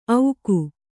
♪ auku